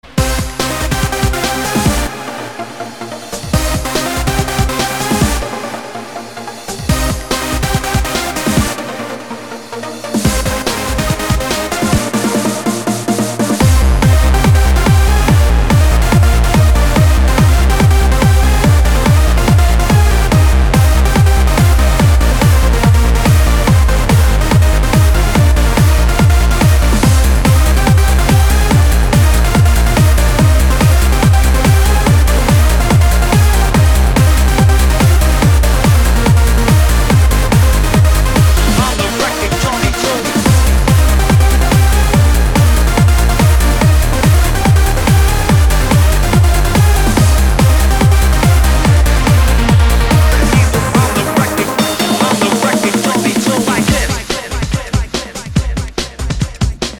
• Качество: 256, Stereo
мужской голос
ритмичные
громкие
dance
Electronic